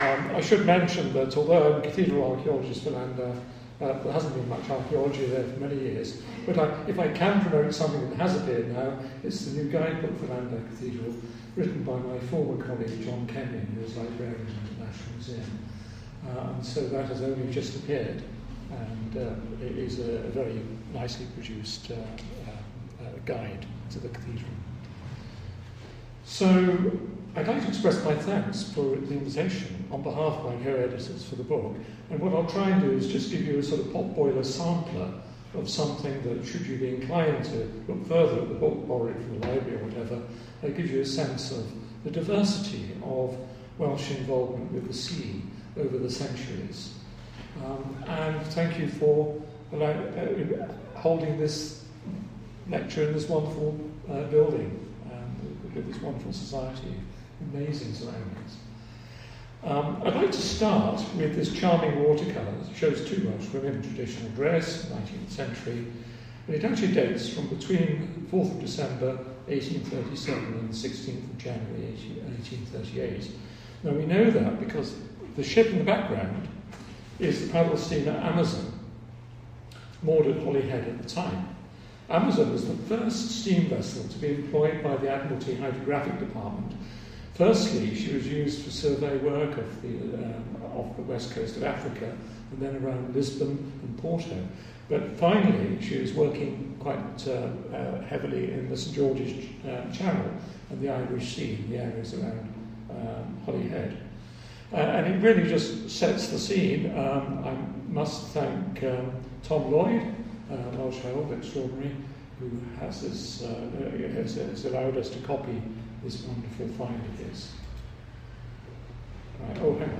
In association with the Montgomeryshire Society The June Gruffydd Memorial Lecture